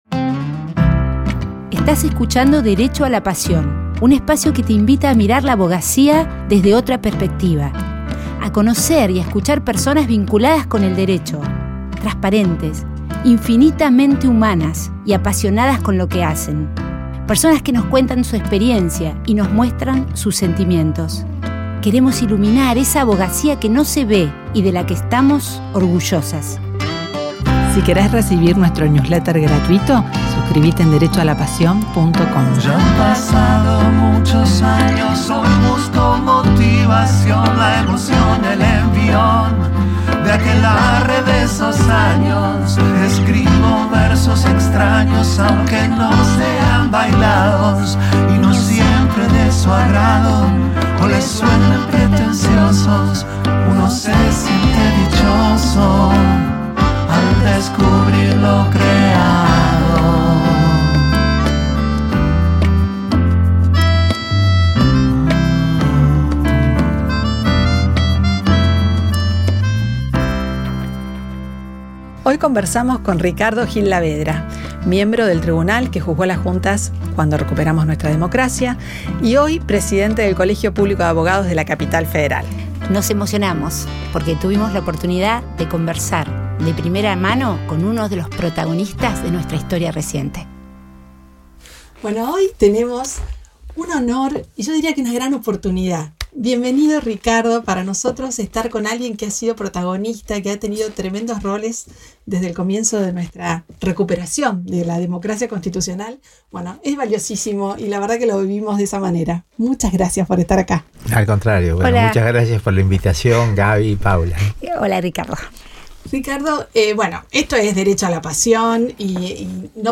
Tuvimos una conversación espectacular con Ricardo Gil Lavedra, miembro del tribunal que juzgó a las juntas cuando recuperamos la democracia y hoy, Presidente del Colegio Público de Abogados de la Capital Federal.
Nos compartió sus aprendizajes personales en el proceso del juicio a las juntas, su vinculo estrecho y profundo con los otros miembros del Tribunal, sus preguntas sobre la naturaleza humana. Conversamos sobre la tarea del abogado, lo valioso de la Democracia Constitucional, la figura de Alfonsin. Al final eligió Let it Be como su canción favorita y cantamos juntos.